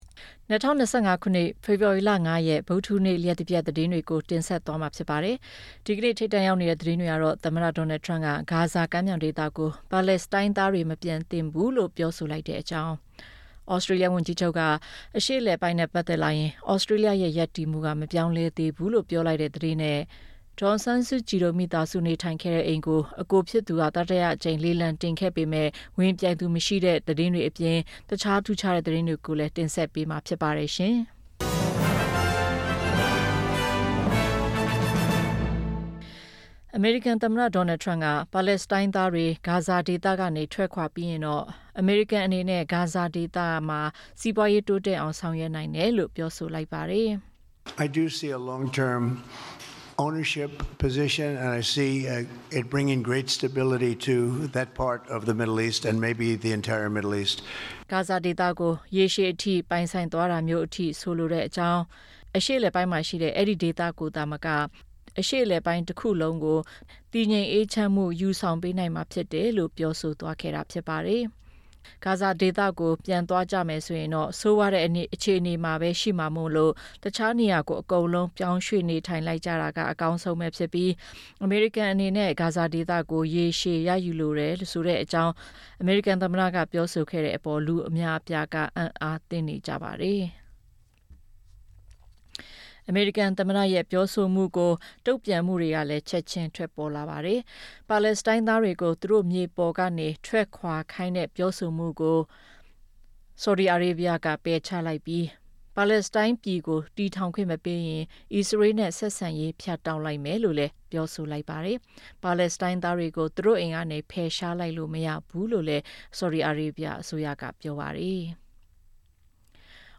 ၂၀၂၅ ဖေဖော်ဝါရီ ၅ ရက် လျှပ်တပြတ် သတင်း